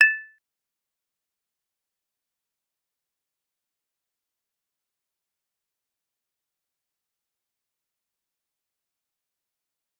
G_Kalimba-A7-mf.wav